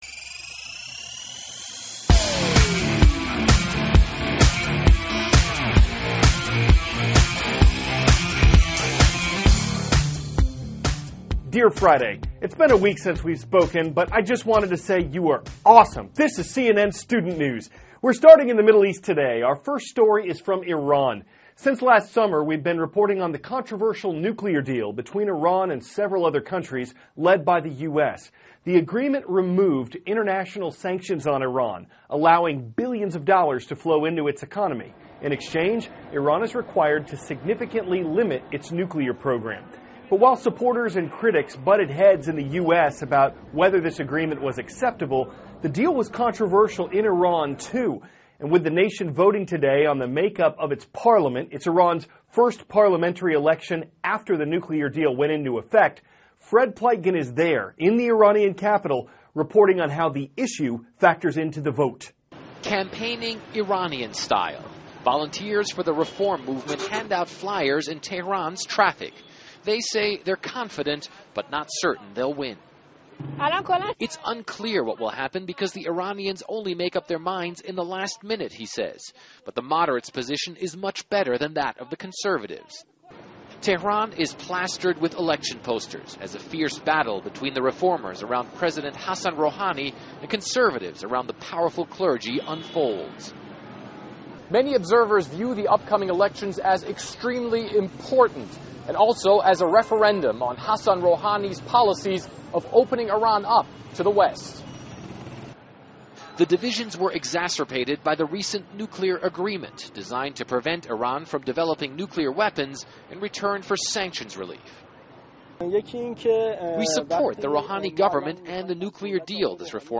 *** CARL AZUZ, cnn STUDENT NEWS ANCHOR: Dear Friday, it`s been a week since we`ve spoken, but I just wanted to say you are awesome!